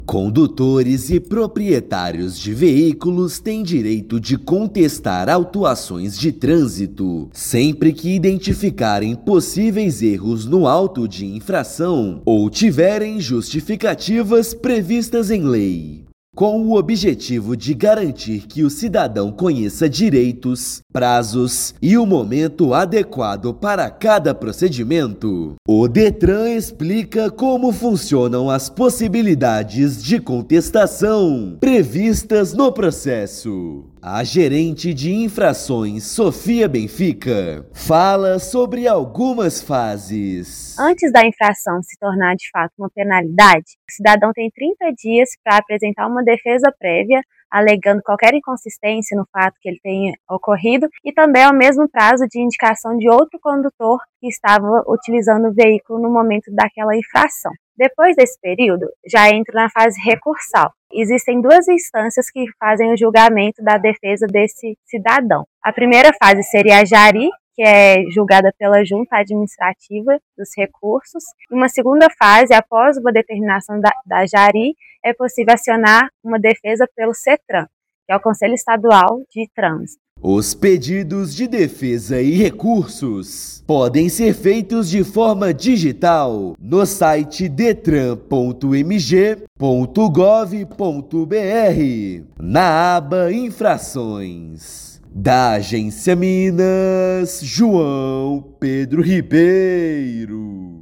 Condutores e proprietários de veículos têm direito a contestar autuações em diferentes etapas do processo administrativo. Ouça matéria de rádio.